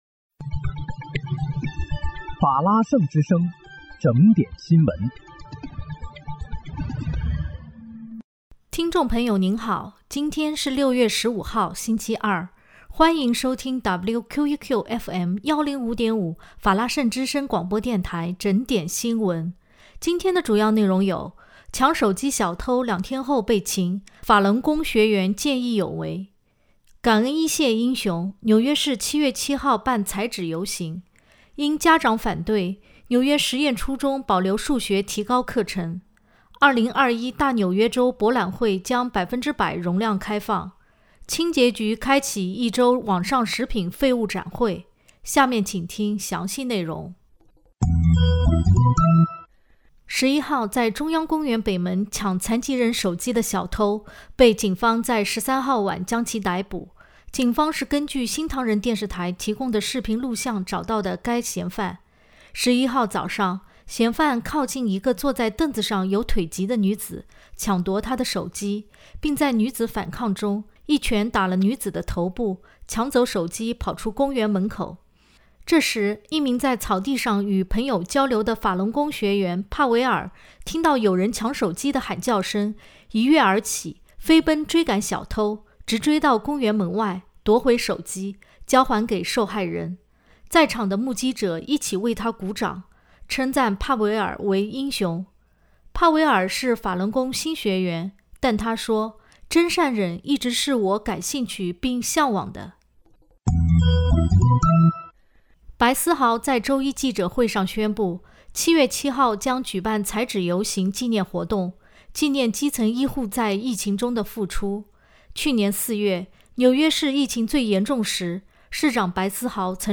6月15日（星期二）纽约整点新闻